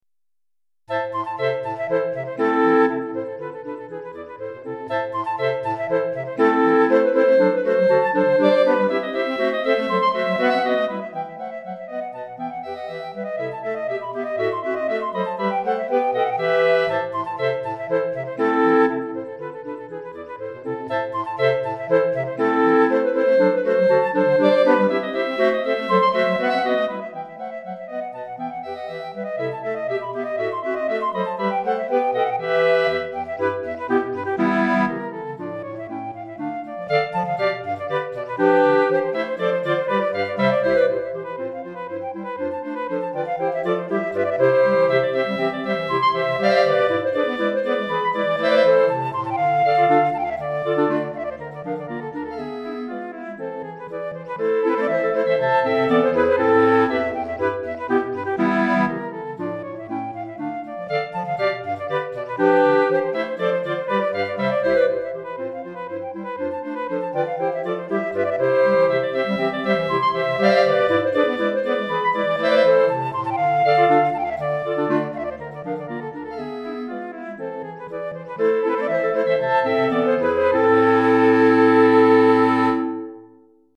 Flûte Traversière et 4 Clarinettes